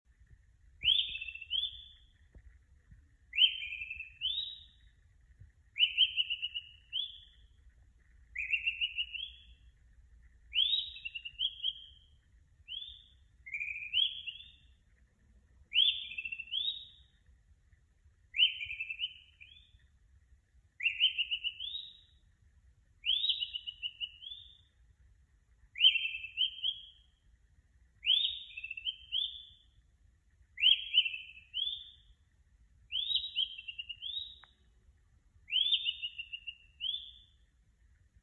My last attempt to make bird sounds. This time I used the Modular System 100m to achieve it.
I played the file to several people who thought they were hearing a real bird.